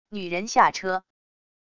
女人下车wav音频